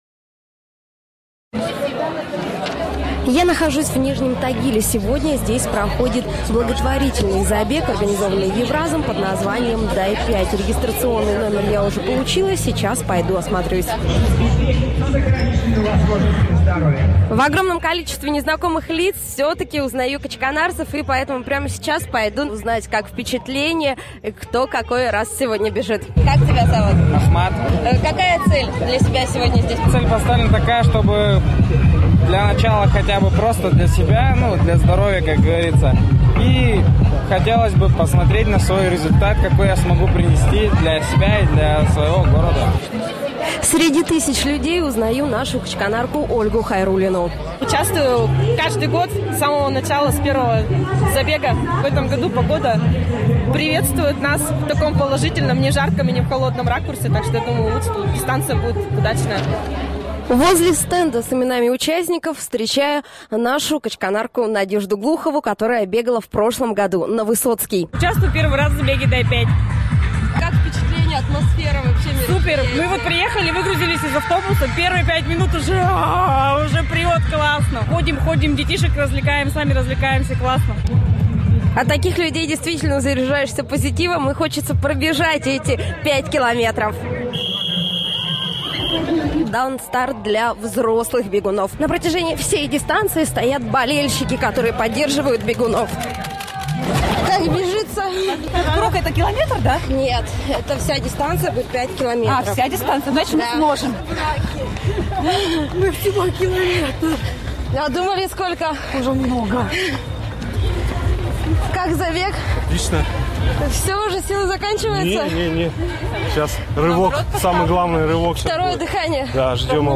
Подробнее о том, как прошло спортивное мероприятие — в репортаже